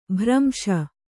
♪ bhramśa